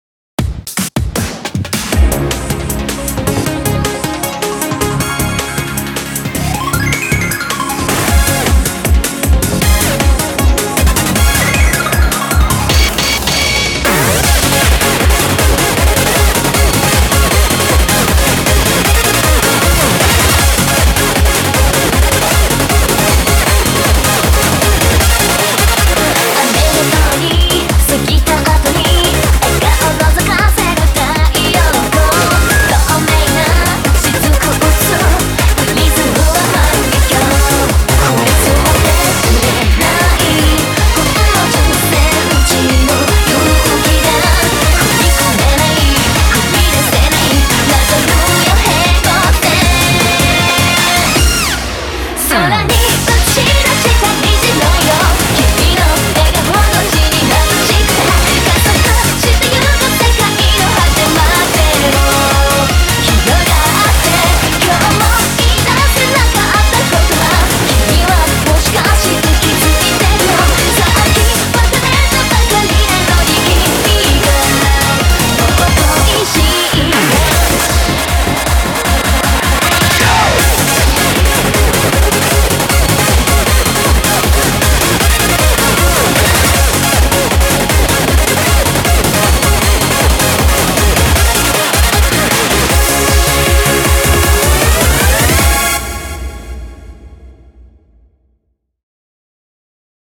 BPM156
Audio QualityPerfect (High Quality)